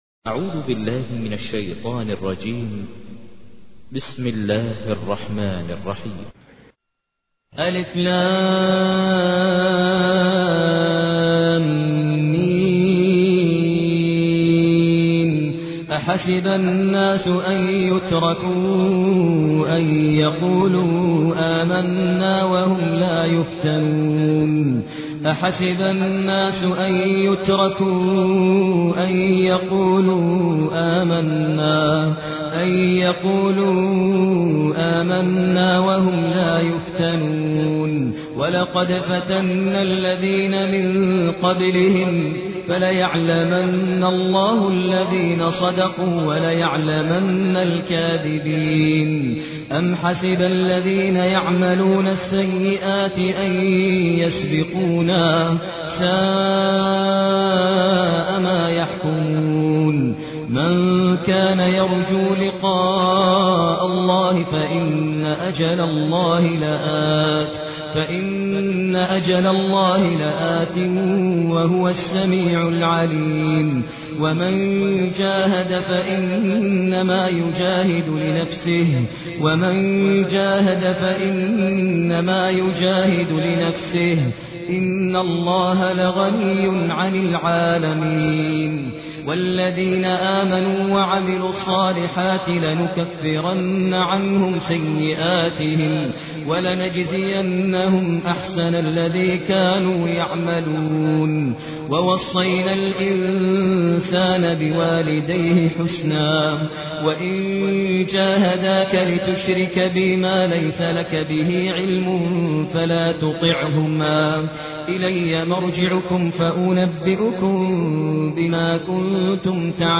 تحميل : 29. سورة العنكبوت / القارئ ماهر المعيقلي / القرآن الكريم / موقع يا حسين